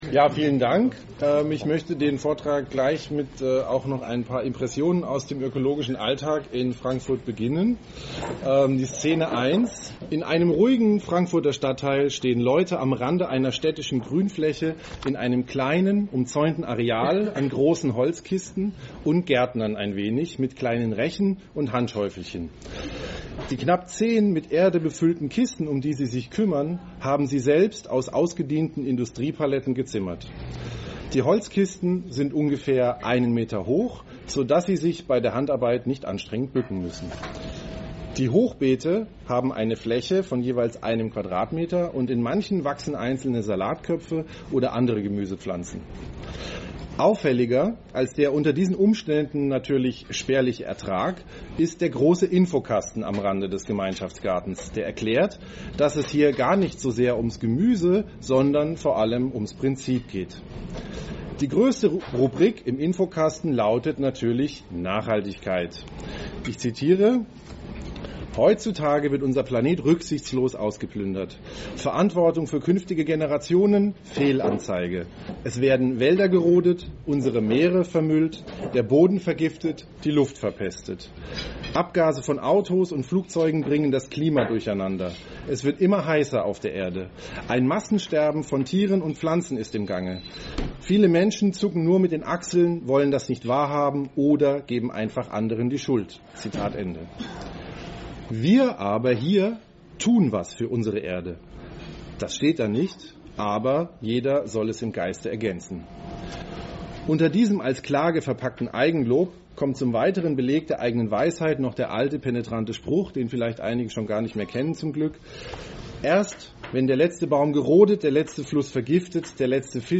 Hier finden sich ausgewählte Audio-Mitschnitte unserer Vortragsveranstaltungen.